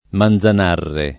Manzanares [sp. manTan#reS] top. (Sp.) e cogn. — come cogn. di famiglie italiane, italianizz. nella pn. [manzan#reS] — come nome del fiume, it. Manzanarre [